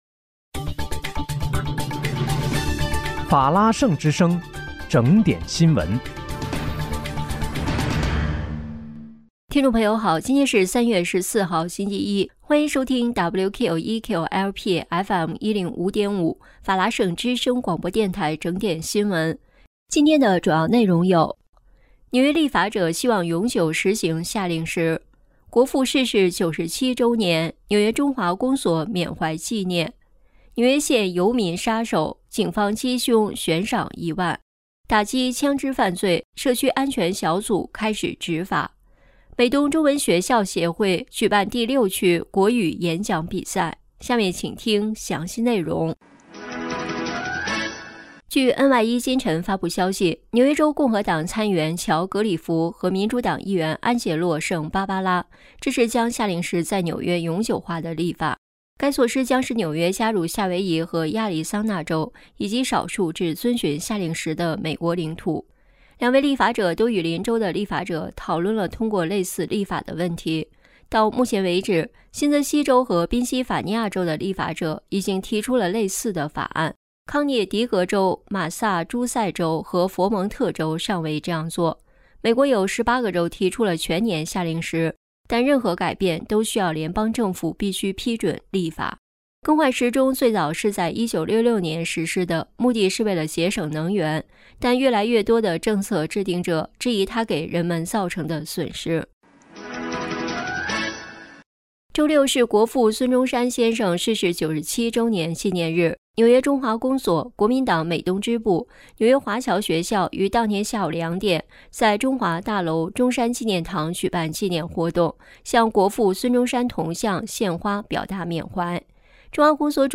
3月14日（星期一）纽约整点新闻